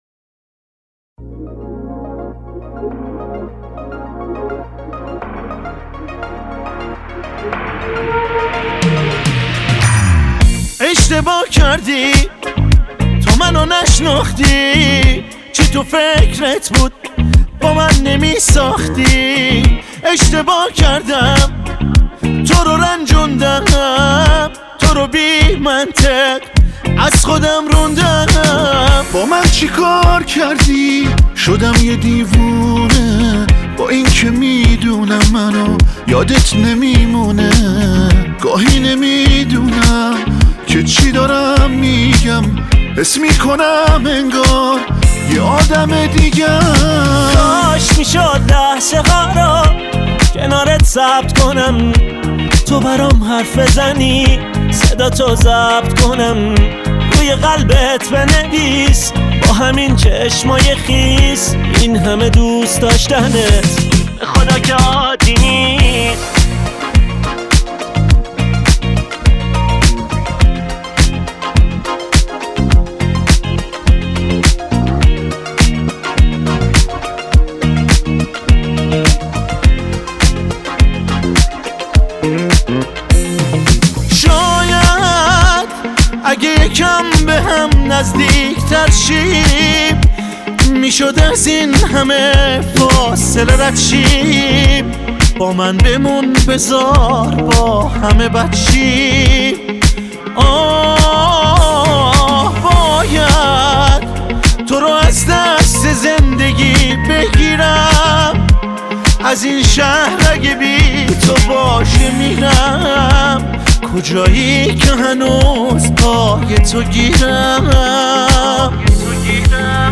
شاد و پرانرژی